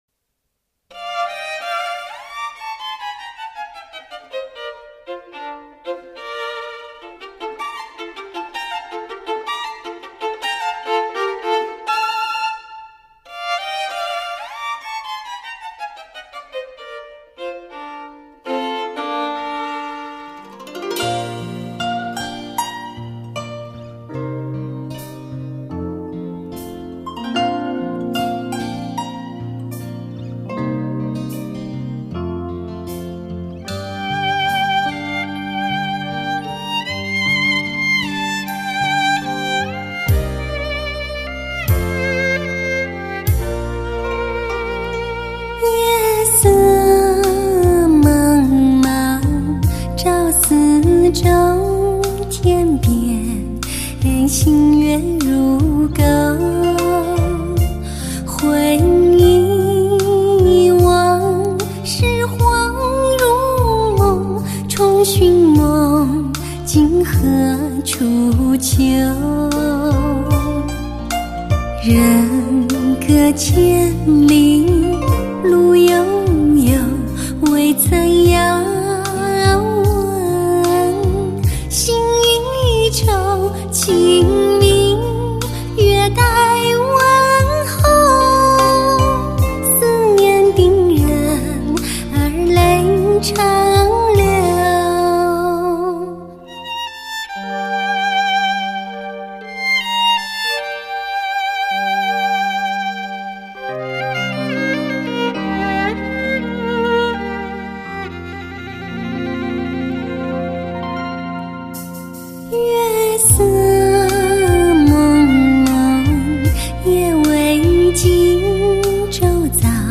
最顶级高保真“发烧”品质，发烧乐坛期待已久的精选之作。
绝对珍稀的古典女声。
15首凸显欣赏品味的经典之作，Hi-Fi音效层次强烈。
古典式情歌，美妙绝伦，绝对不容错过的发烧尚品。